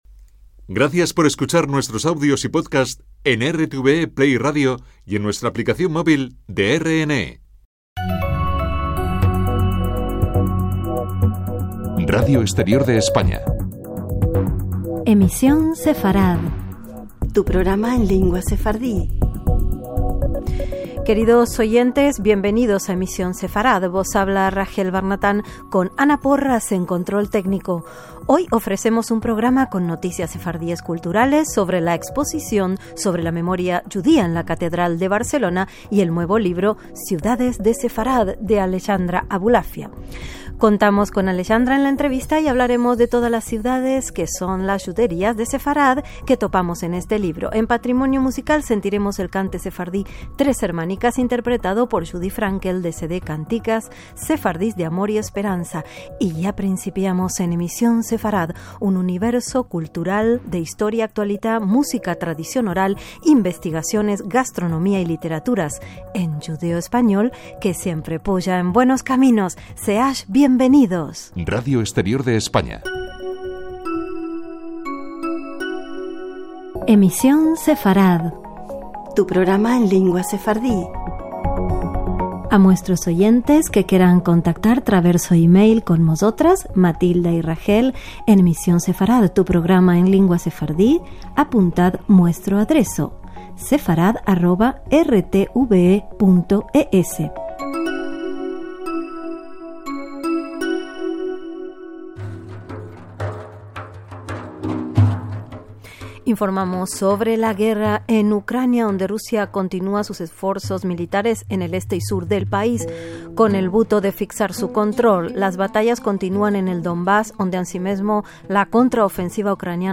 caminos_de_sefarad_rtve.mp3